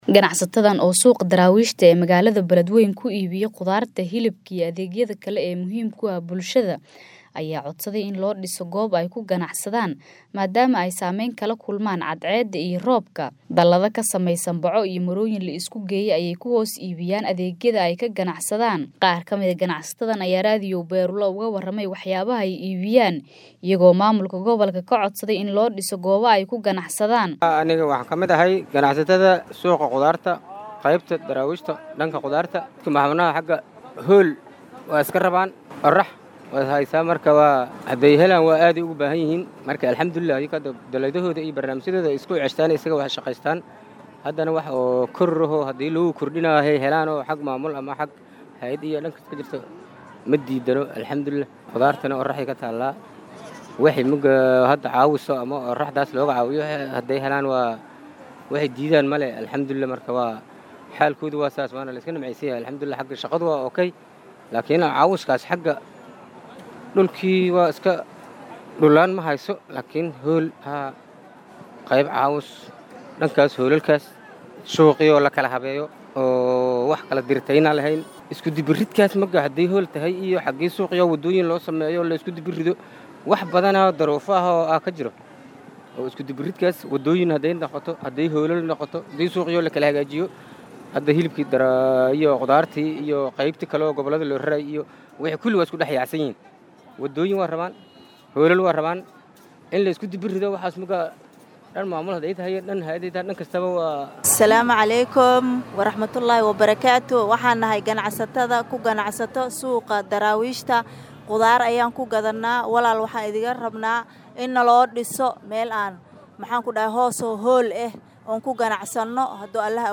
Warbixintan